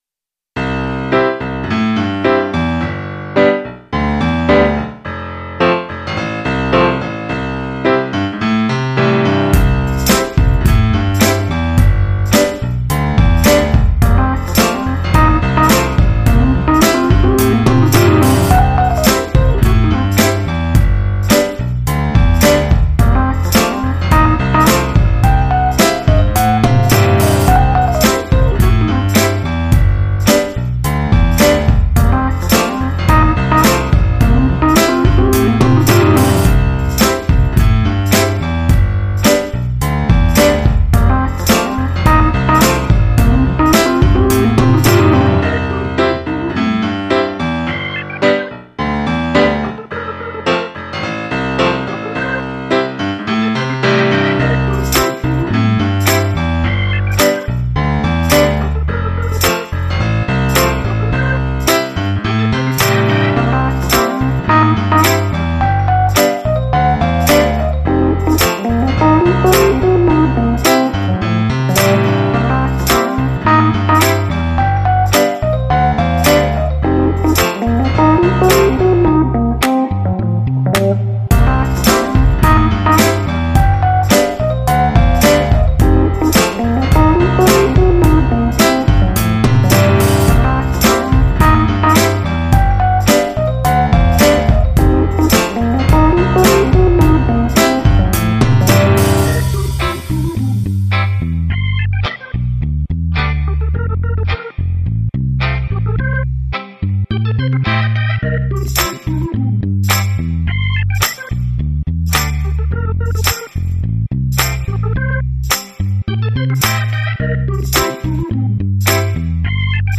это завораживающий трек в жанре ретро-соул